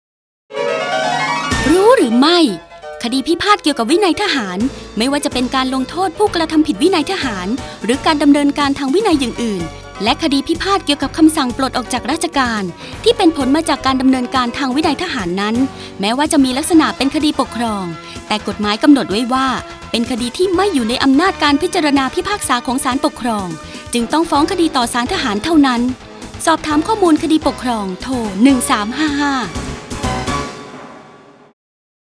สารคดีวิทยุ ชุดคดีปกครองชวนรู้ ตอนคดีที่ไม่อยู่ในอำนาจของศาลปกครอง 1